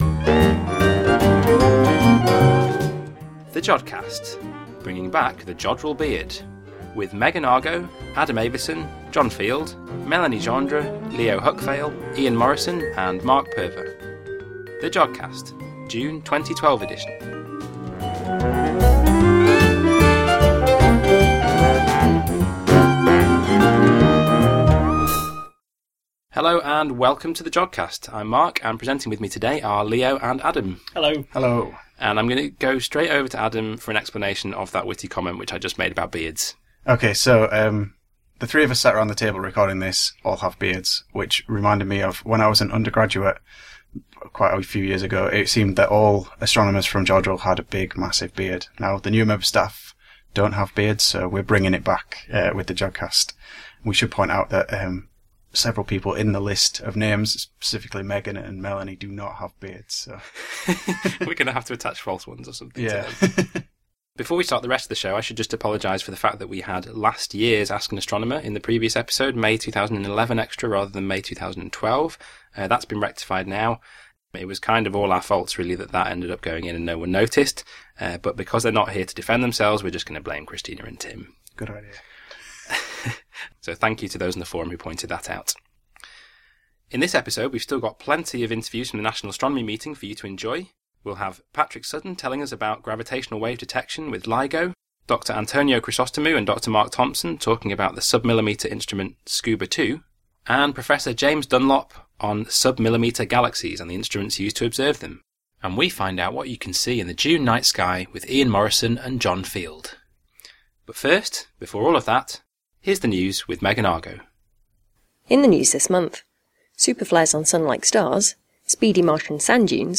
This month, we continue our feast of interviews from the National Astronomy Meeting.